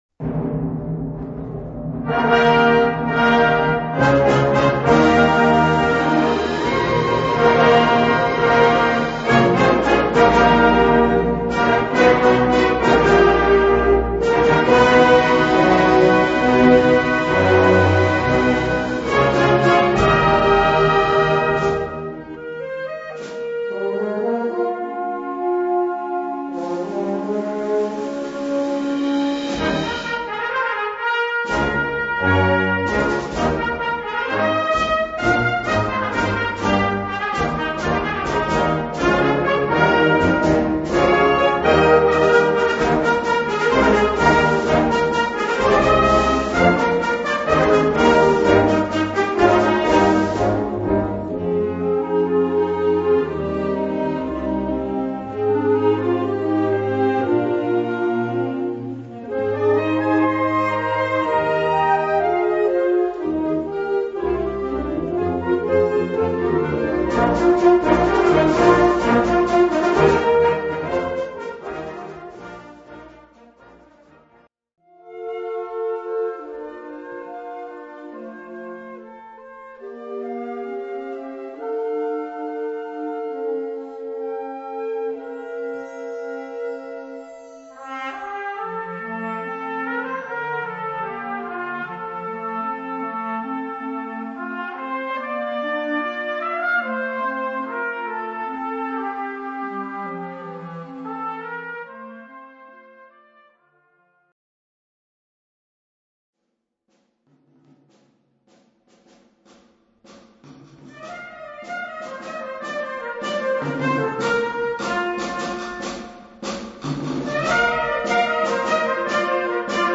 Catégorie Harmonie/Fanfare/Brass-band
Sous-catégorie Ouvertures (œuvres originales)
Instrumentation Ha (orchestre d'harmonie)